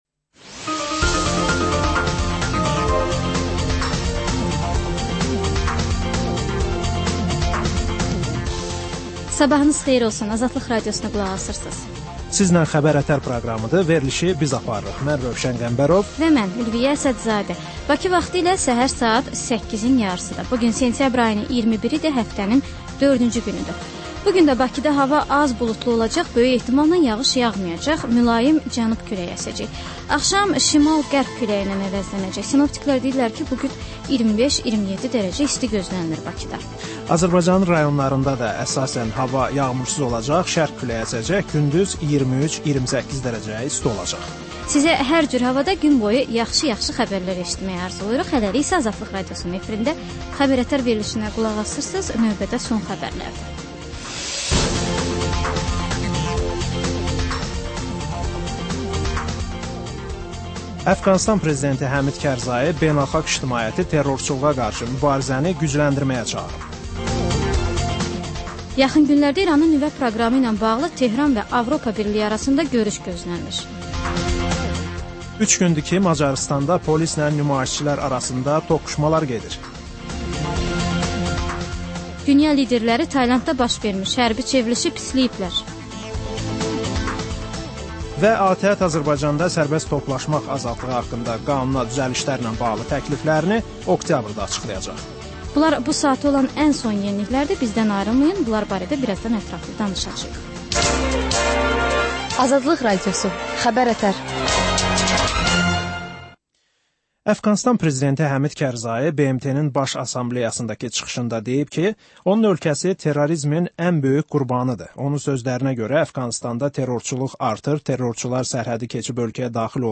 Səhər-səhər, Xəbər-ətərş Xəbərlər, reportajlar, müsahibələr